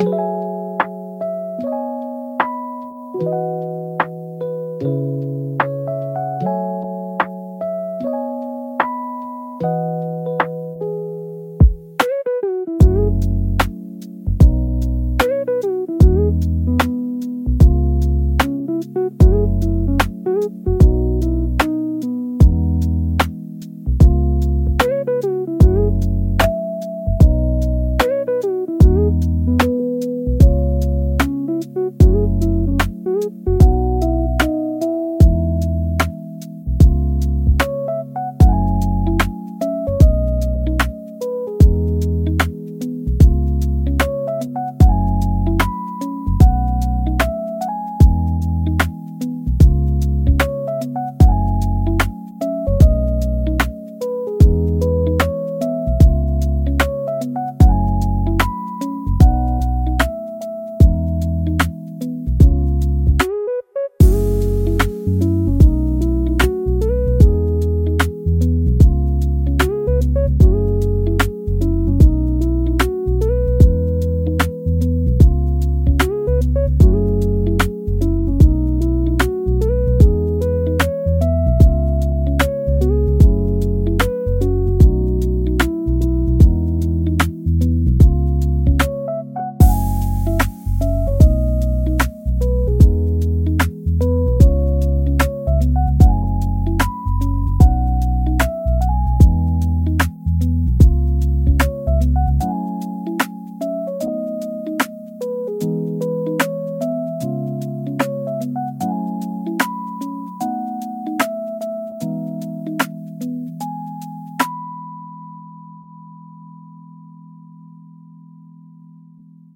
チルアウトは、ゆったりとしたテンポと滑らかで広がりのあるサウンドが特徴のジャンルです。
リラックス効果の高いメロディと穏やかなリズムにより、心地よい安らぎの空間を作り出します。